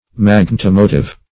Search Result for " magnetomotive" : The Collaborative International Dictionary of English v.0.48: Magnetomotive \Mag`net*o*mo"tive\, a. [Magneto- + motive, a.]
magnetomotive.mp3